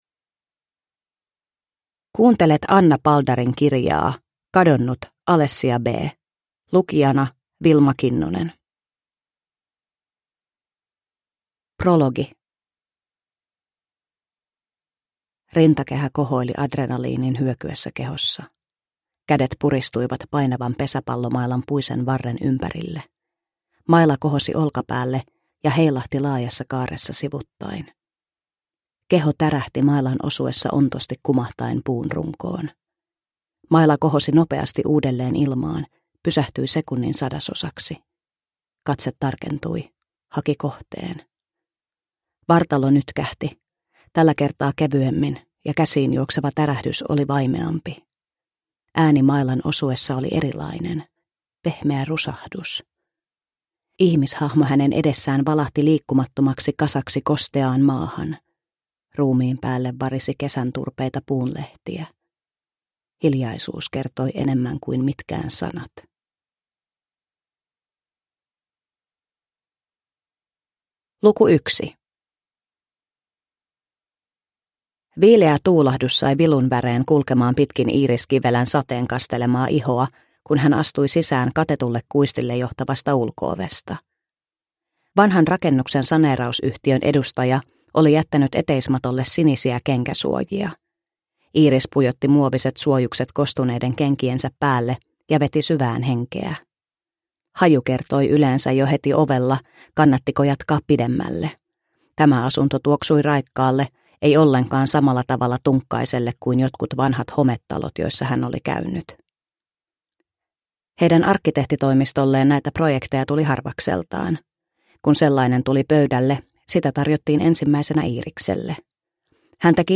Kadonnut: Alessia B – Ljudbok – Laddas ner